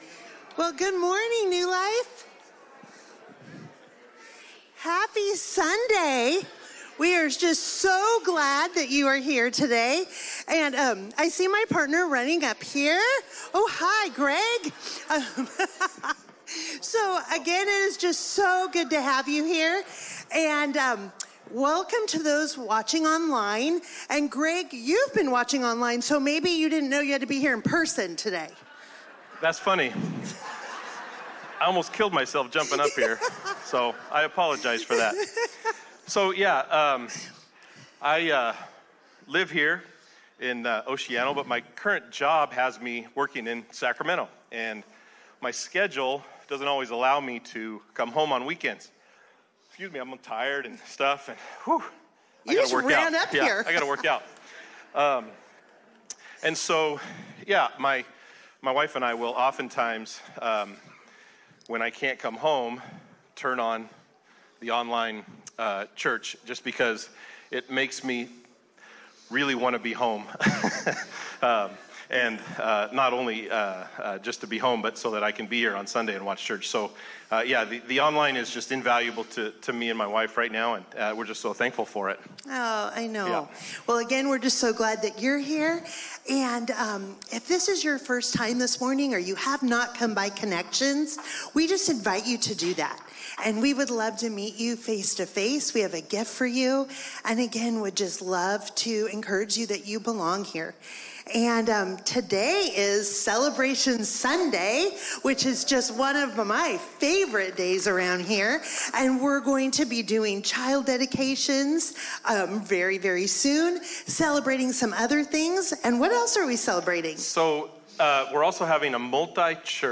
A message from the series "No Longer Strangers."